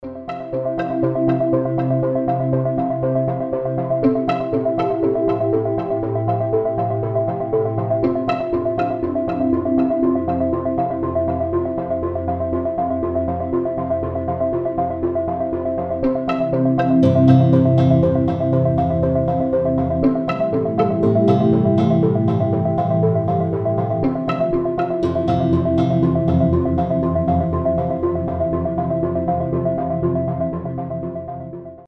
• Качество: 320, Stereo
гитара
Electronic
без слов
красивая мелодия
chillwave
Ambient
Стиль: chillwave, atmospheric ambient